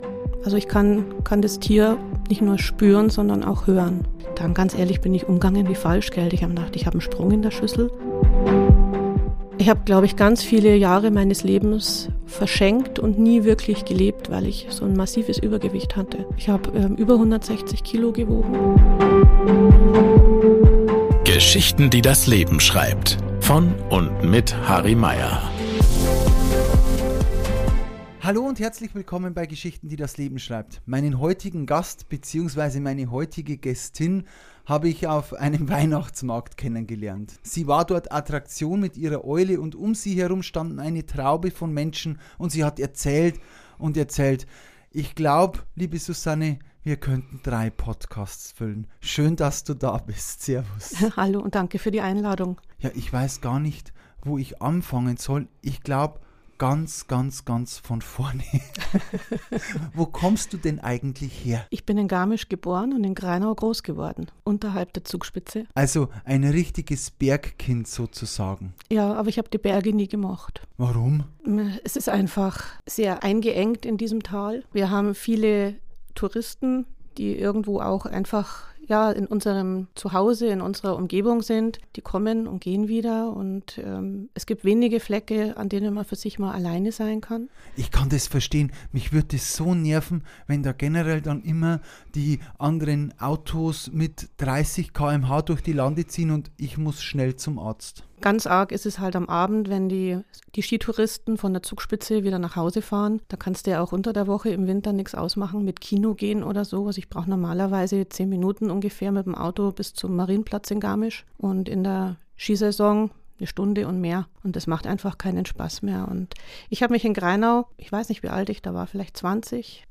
Ein inspirierendes Gespräch über Veränderungen, Selbstdisziplin, die Liebe zu Tieren – und die besondere Verbindung zwischen Mensch und Natur.